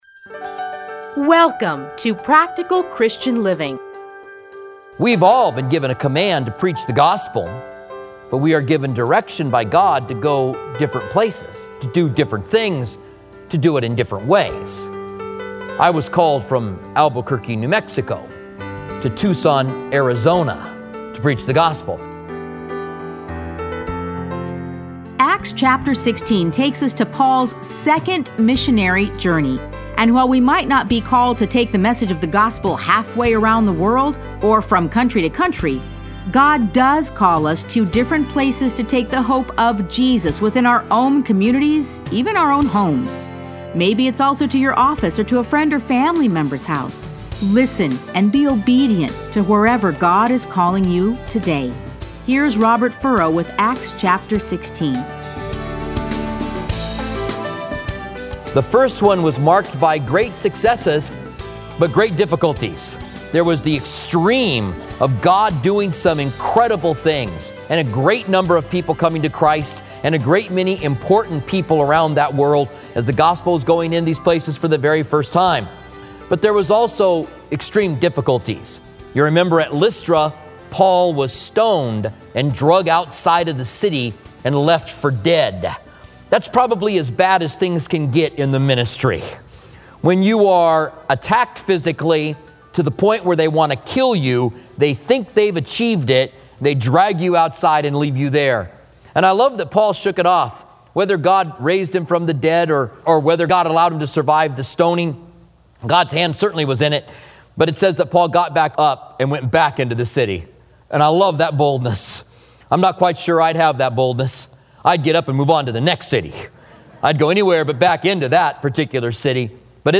Listen to a teaching from Acts 16.